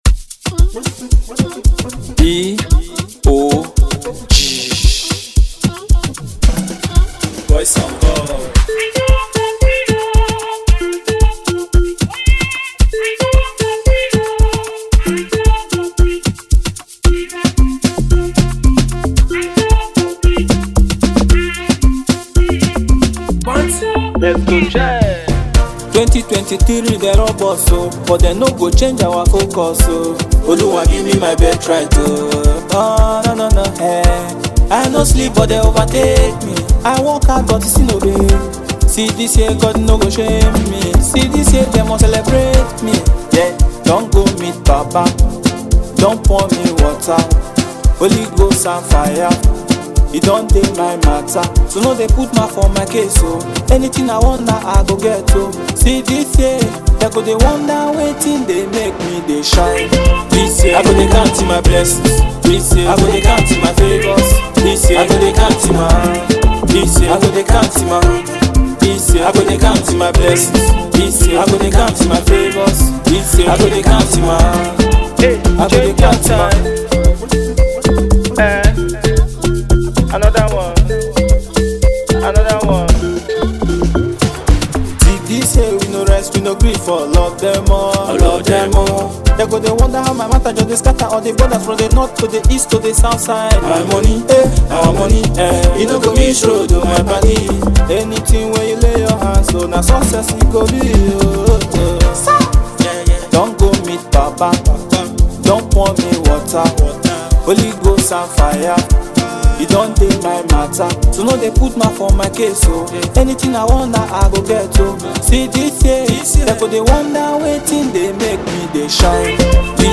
Afrobeats single